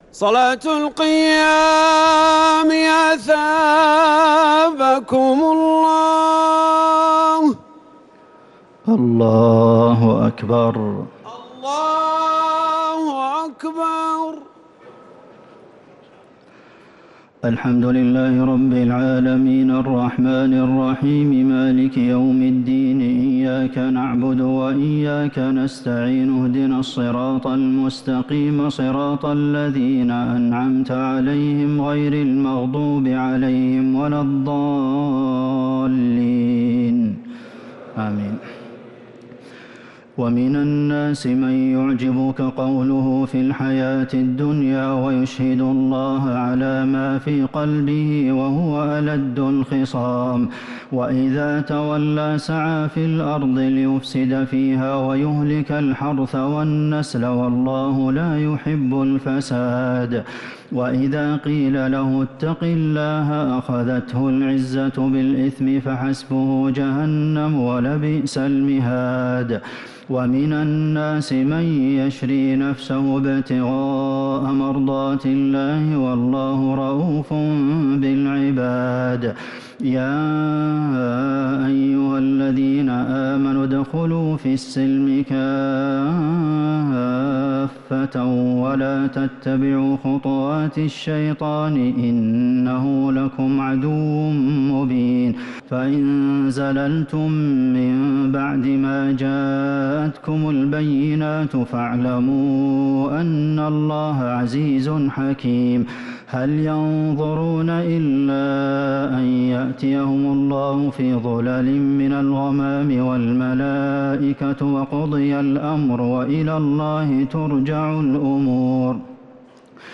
تراويح ليلة 3 رمضان 1447هـ من سورة البقرة {204-248} Taraweeh 3rd night Ramadan 1447H > تراويح الحرم النبوي عام 1447 🕌 > التراويح - تلاوات الحرمين